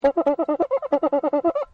バテリア＆サンバ楽器
cuica.mp3